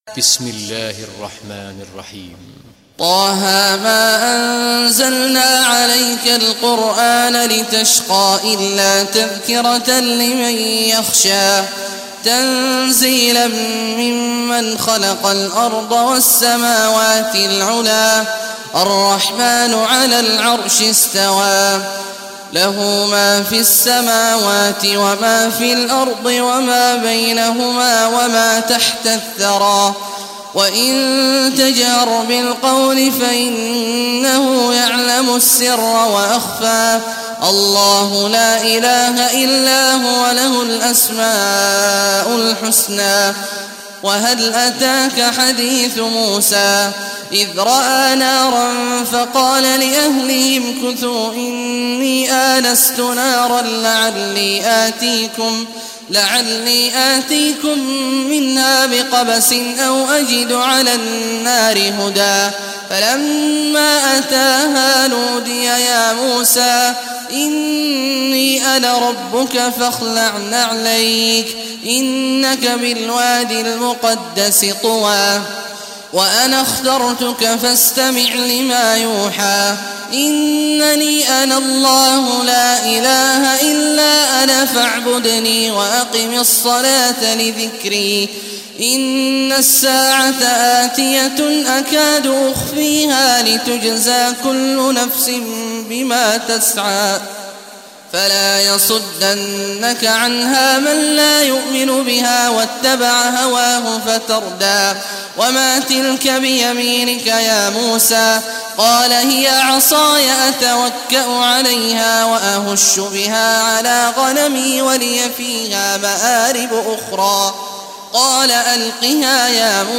Surah Taha Recitation by Sheikh Abdullah al Juhany
Surah Taha, listen or play online mp3 tilawat / recitation in Arabic in the beautiful voice of Sheikh Abdullah Awad al Juhany.